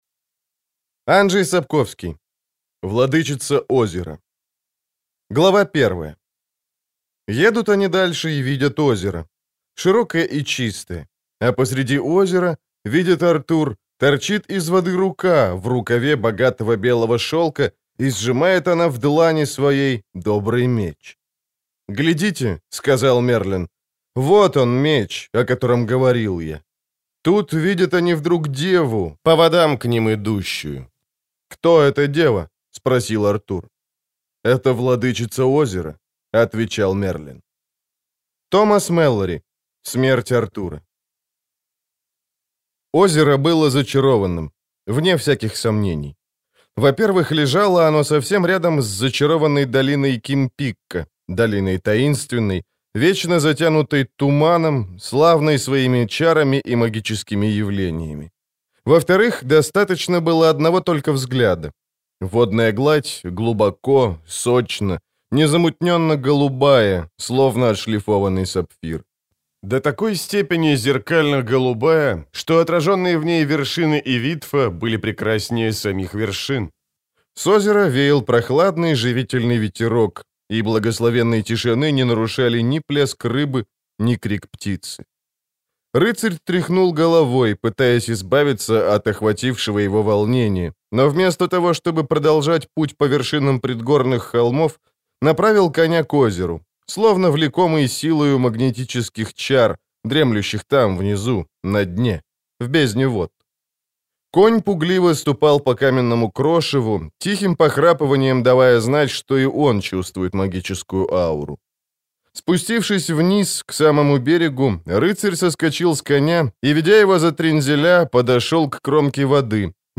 Аудиокнига Владычица Озера
Качество озвучивания весьма высокое.